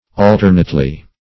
Alternately \Al*ter"nate*ly\, adv.